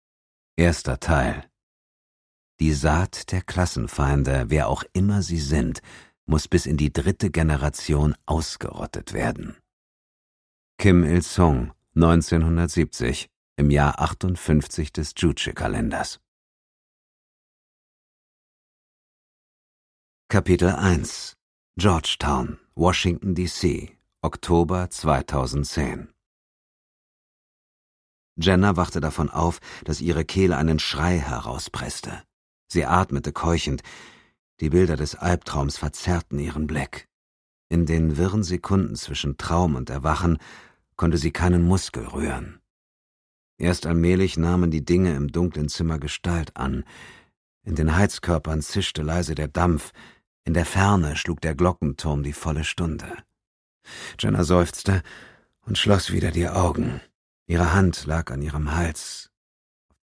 D.B. John: Stern des Nordens (Ungekürzte Lesung)
Produkttyp: Hörbuch-Download
Gelesen von: Dietmar Wunder